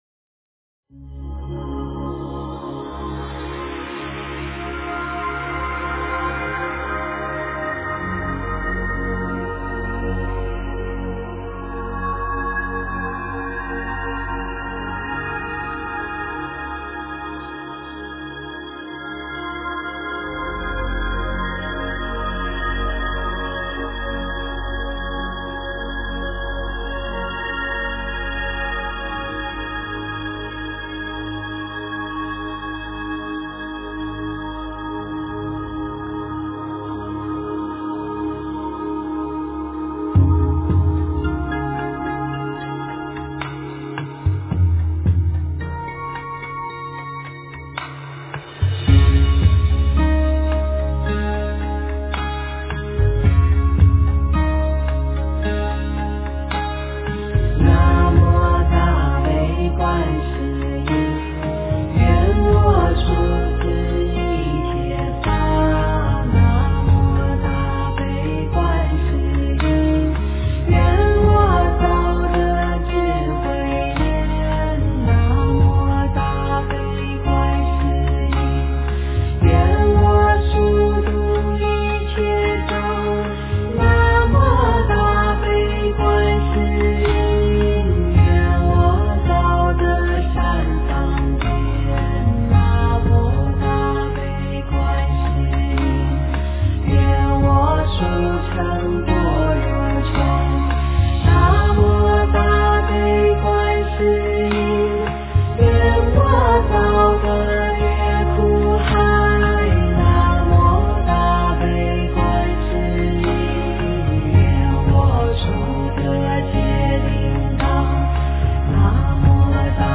大悲咒 诵经 大悲咒--佛经 点我： 标签: 佛音 诵经 佛教音乐 返回列表 上一篇： 金刚萨埵心咒 下一篇： 心经 相关文章 心经--观音颂 心经--观音颂...